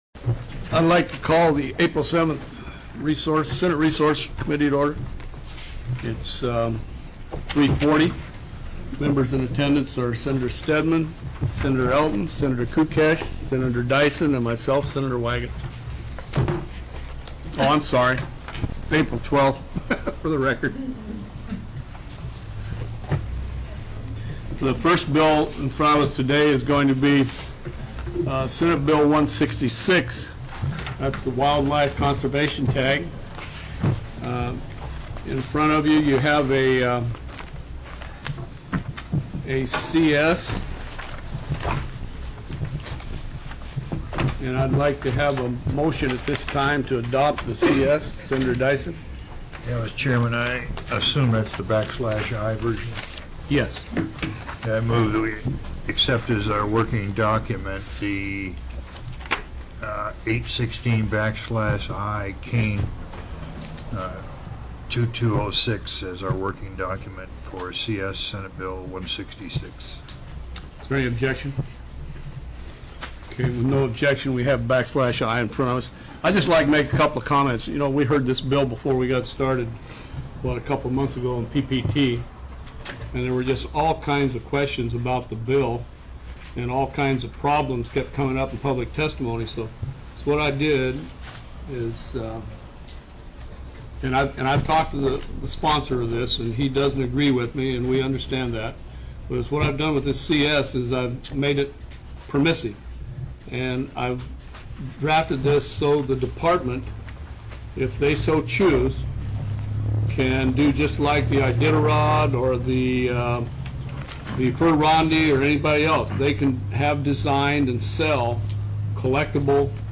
04/12/2006 03:30 PM Senate RESOURCES